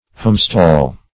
Search Result for " homestall" : The Collaborative International Dictionary of English v.0.48: Homestall \Home"stall`\, n. [AS. h[=a]msteall.] Place of a home; homestead.